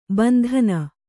♪ bandhana